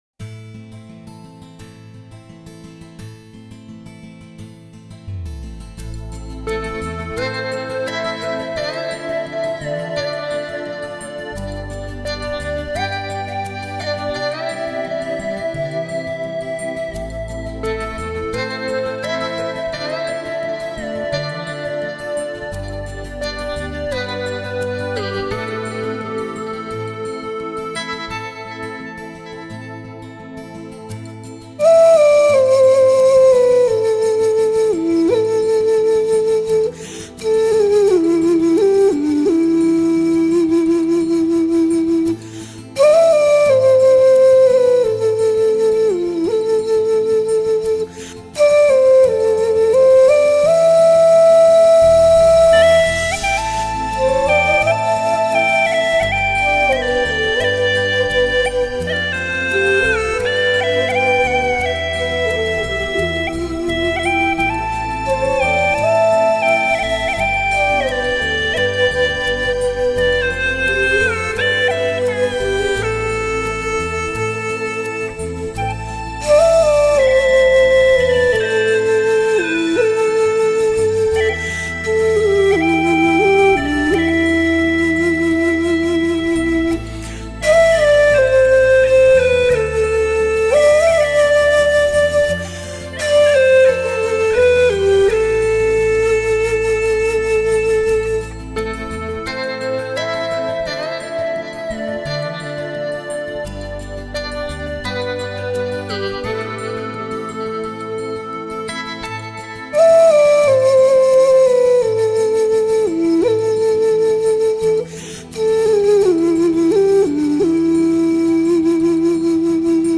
专辑英文名: 葫芦丝演奏
艺术家: 纯音乐
葫芦丝最新现代发烧碟，精彩的现代配器，愿味的民族风格，古典与现代完美的结合，让你感受全新的音乐风情，亲切婉转，温馨感人。
当悠扬的葫芦丝在竹林深处响起，和着多情的月光将成为你我脑海里最美的一幅图画。
优美葫芦丝 韵味浓郁 收藏极品
秀丽而不浓妆艳抹 活泼而又含蓄深情